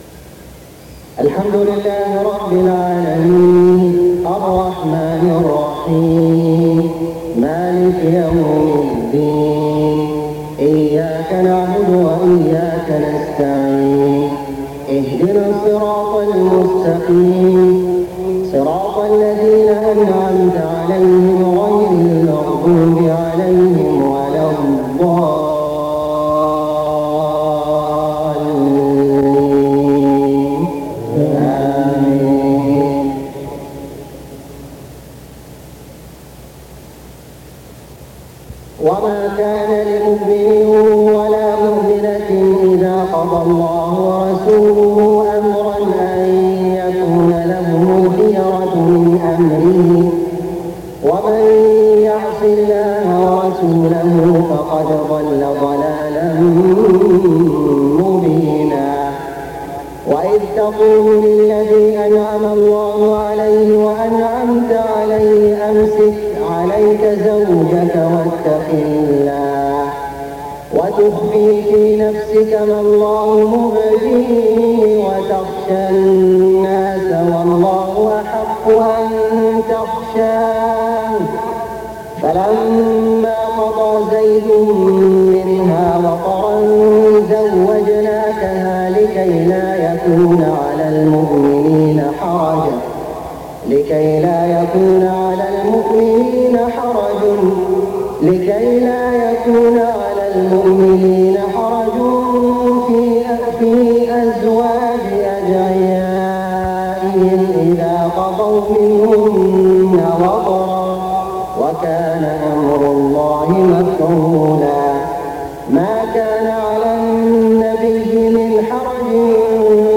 صلاة العشاء 17 محرم 1429هـ من سورة الأحزاب 36-48 > 1429 🕋 > الفروض - تلاوات الحرمين